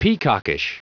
Prononciation du mot peacockish en anglais (fichier audio)
Prononciation du mot : peacockish